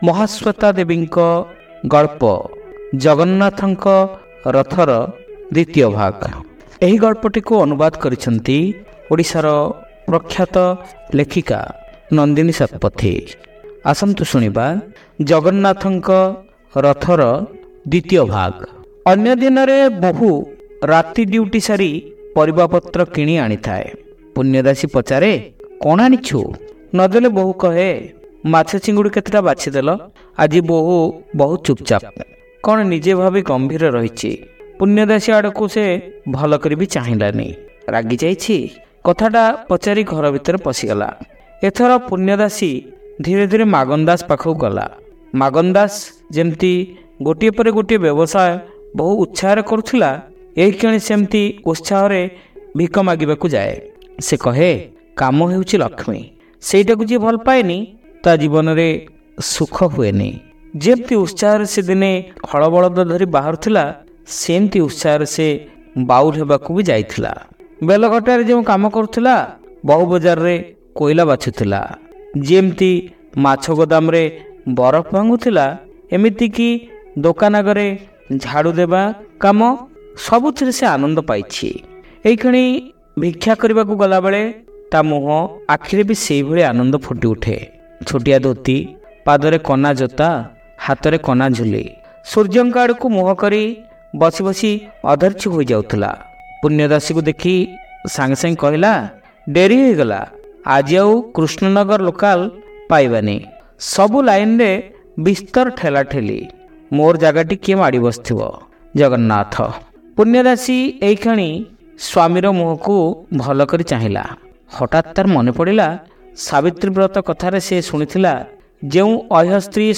Audio Story : Jagannatha nka Ratha 2